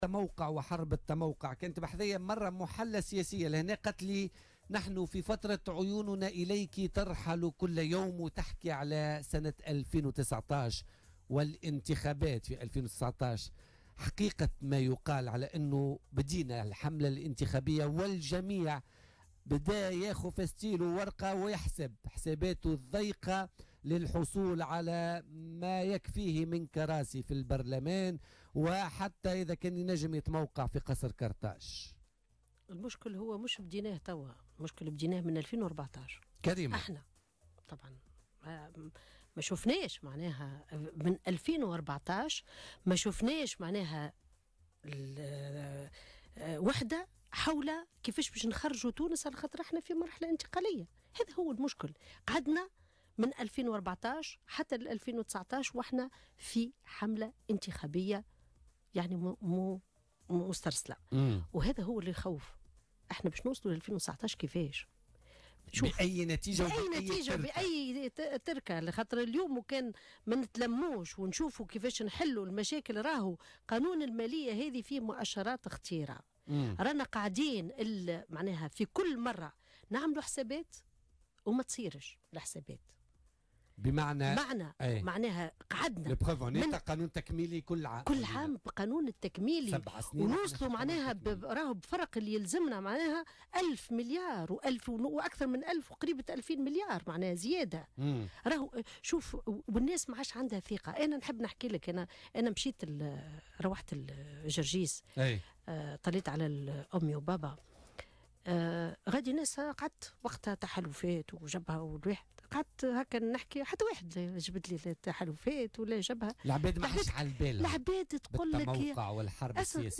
أكدت القيادية في حزب افاق تونس سميرة مرعي ضيفة بولتيكا اليوم الخميس 23 نوفمبر 2017 في تعليقها على حكومة الوحدة الوطنية أن الوطنية موجودة و الوحدة مفقودة .